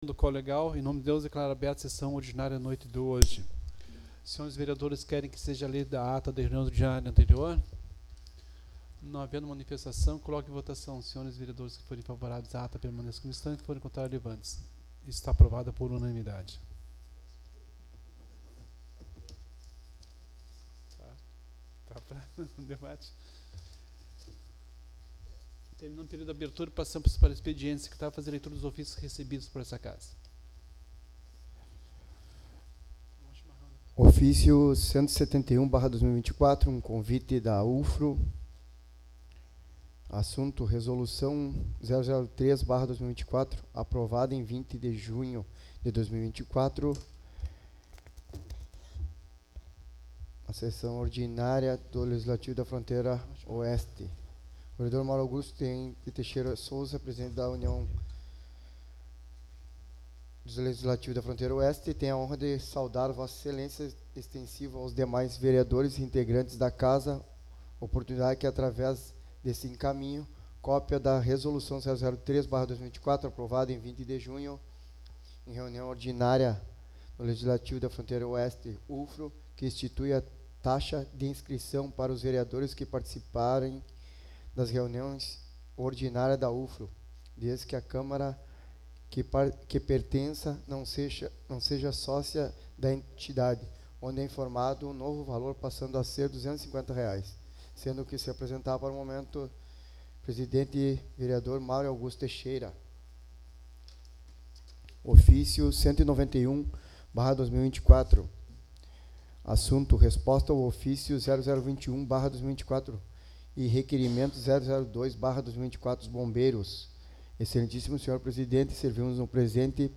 Sessão Ordinária 08/07/2024